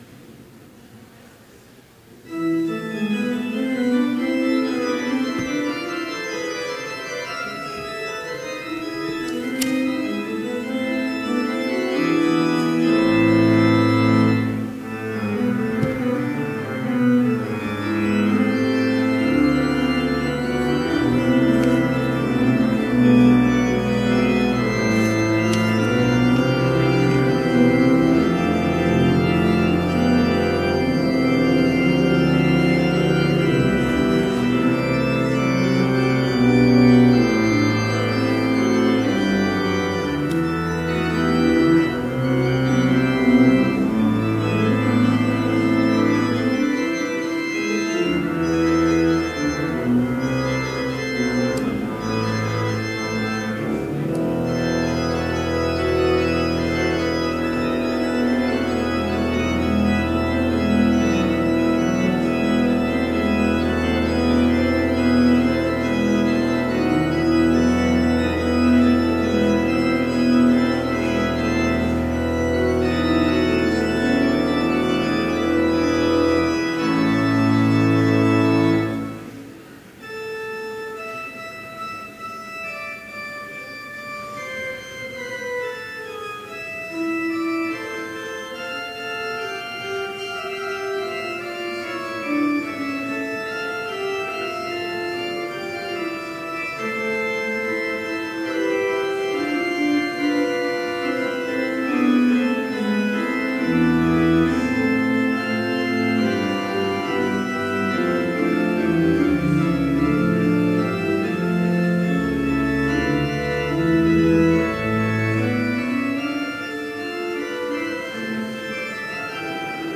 Complete service audio for Chapel - November 2, 2017